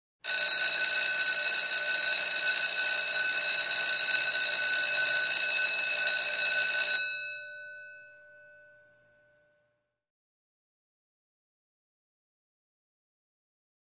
School Bell; Long Ring, Interior Hall, Close Perspective.